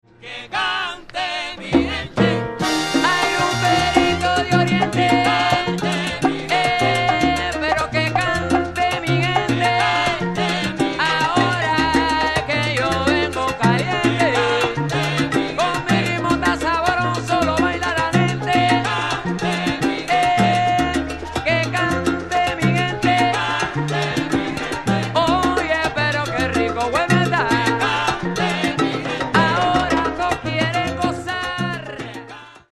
Salsa des années 70 :